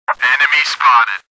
Category: Video Game Ringtones